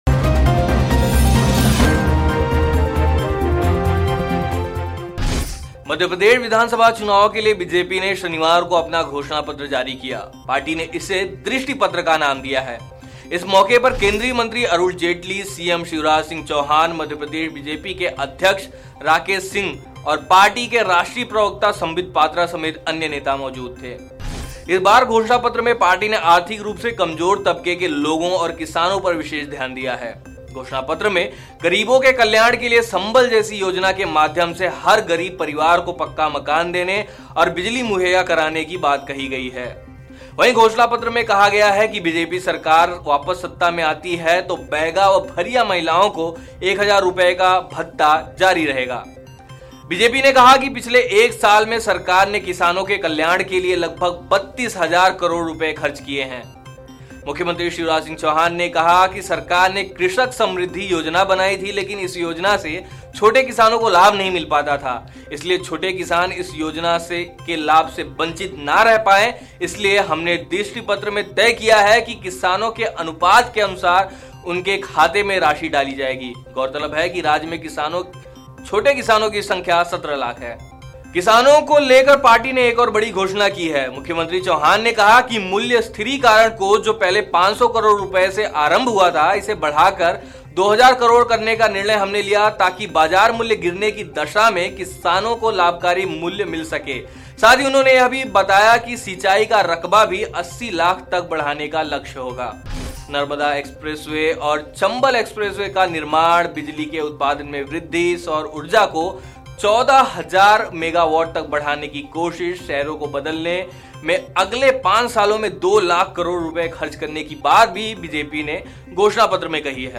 न्यूज़ रिपोर्ट - News Report Hindi / एमपी बीजेपी घोषणापत्र: छात्राओं को स्कूटी, गरीबों को मकान और हर साल 10 लाख रोजगार देगी भाजपा सरकार